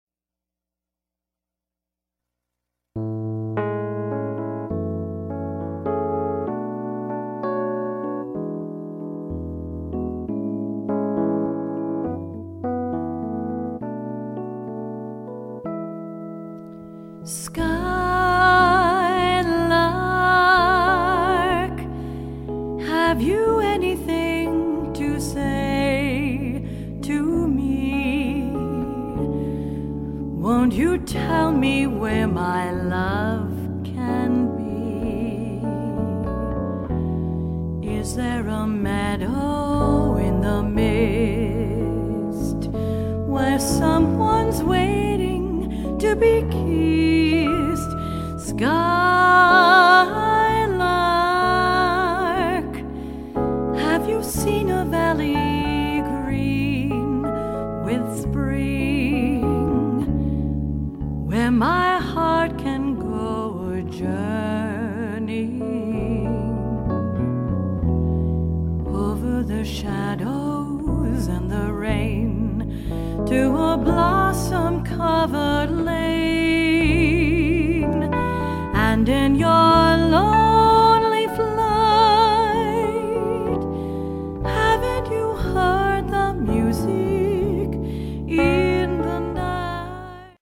rich vocals
jazz act